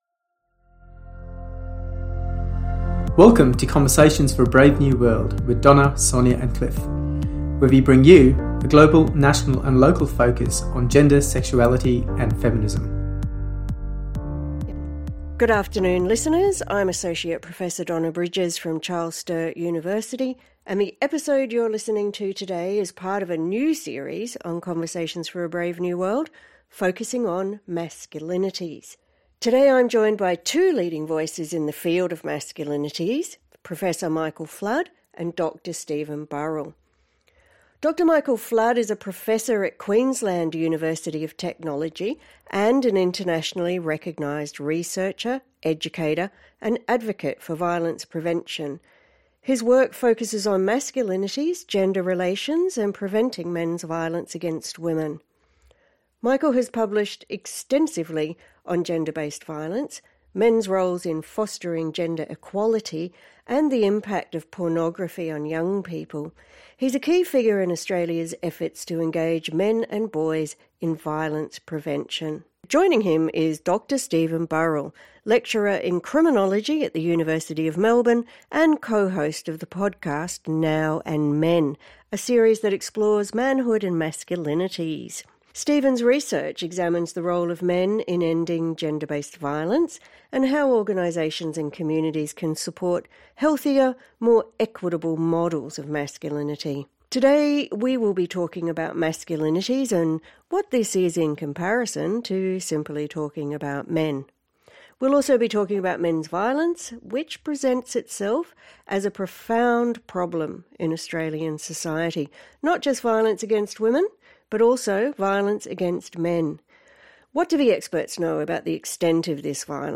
This timely conversation highlights the need to transform masculinities as part of the broader struggle for gender justice. The episode focuses on men themselves, as well as the profound problem of men’s violence, both against women and against other men, in Australian society.